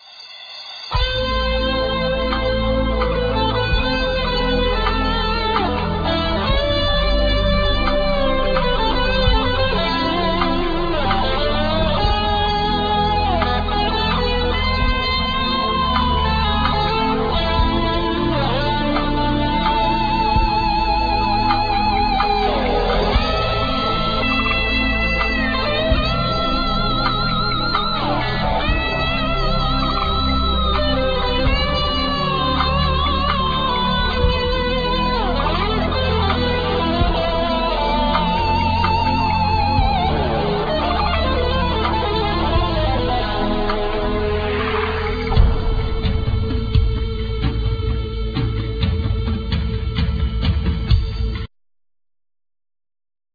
Guitar(Spanish,Electric,Acoustic),Banjo,Percussions
Flauta de adelfa,Tin whistle,Fairlight III
Piano
Cello
Vocals